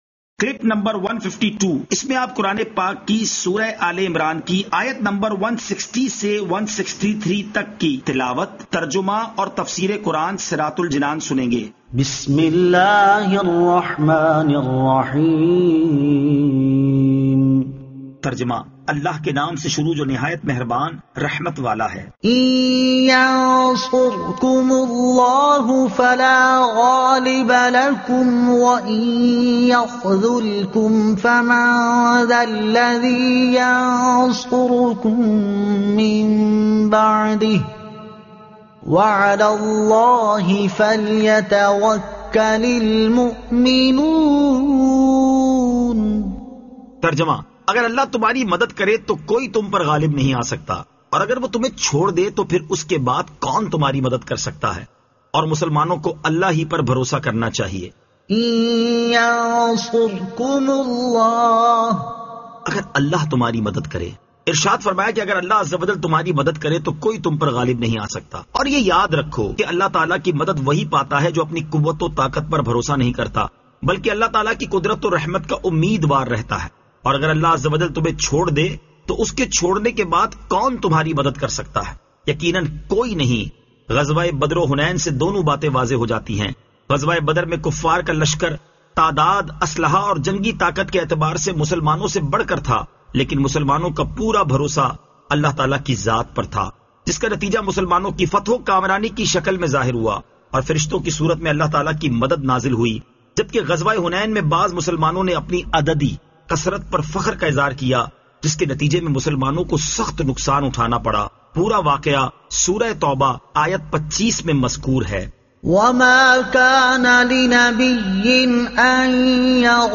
Surah Aal-e-Imran Ayat 160 To 163 Tilawat , Tarjuma , Tafseer